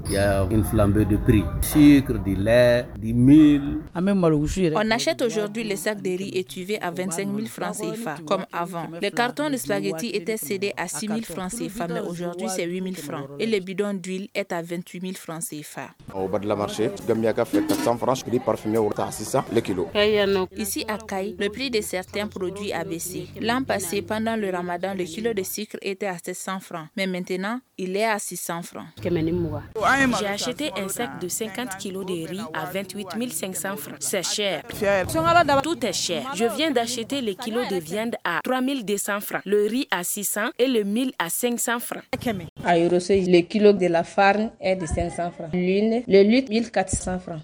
Nous nous trouvons au marché Dibidani, situé dans la commune III du district de Bamako.